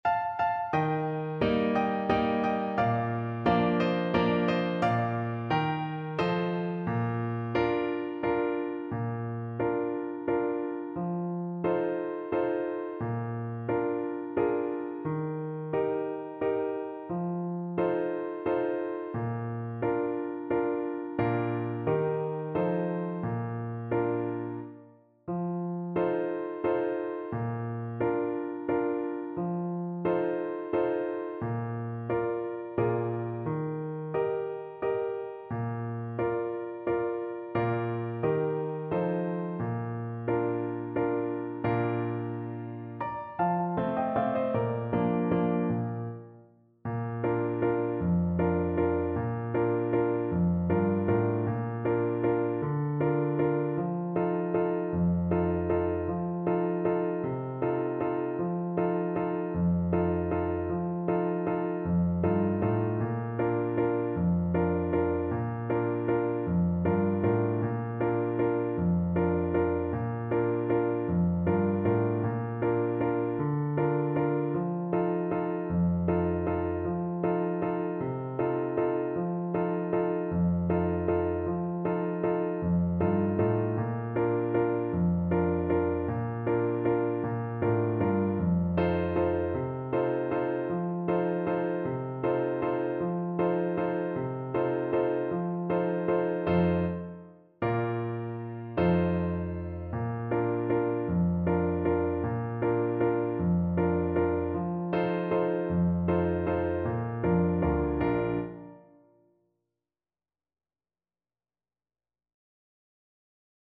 3/4 (View more 3/4 Music)
Slow =c.88